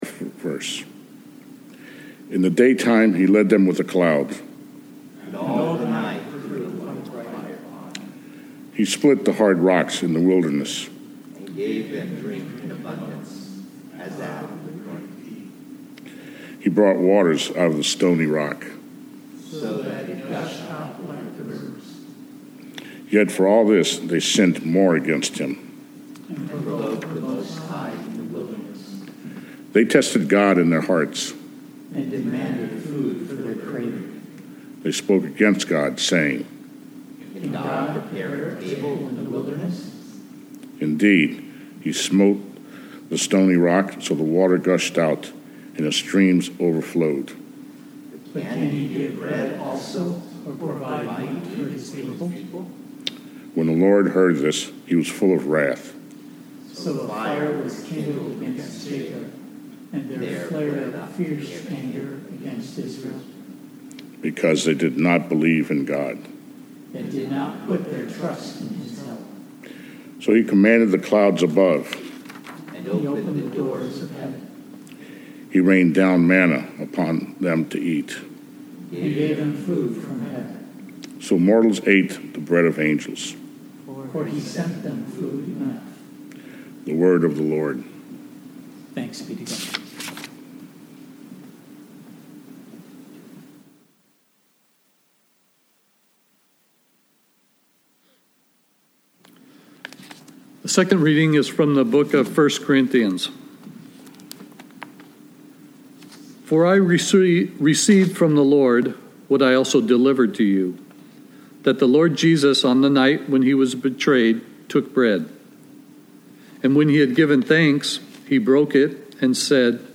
Maundy Thursday